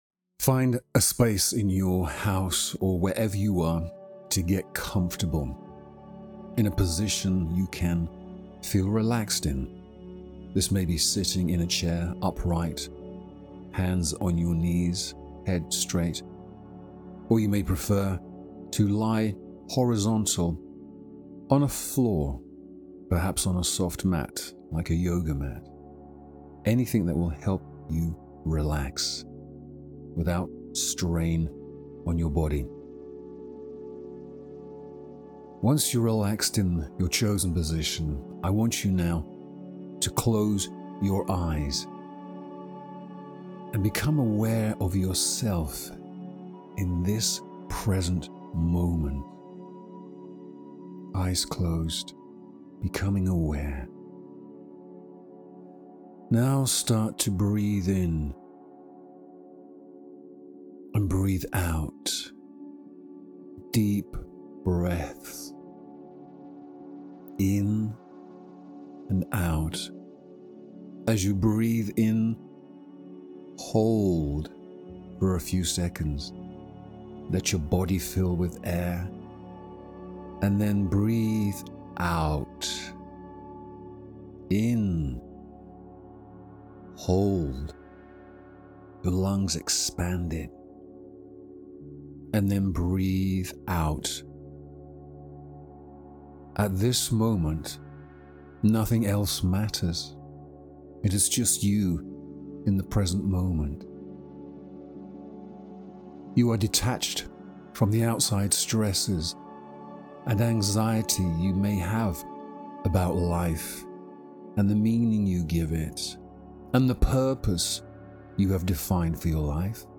About: This free guided meditation connects with your unconscious to explore and release meaning and purpose for living in accordance with your values and beliefs.
Allow this guided meditation to speak deep to your unconscious and reprogram how you think about yourself and how you view the world inside and outside of you.
purpose-meditation.mp3